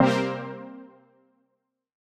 Index of /musicradar/future-rave-samples/Poly Chord Hits/Straight
FR_JPEGG[hit]-C.wav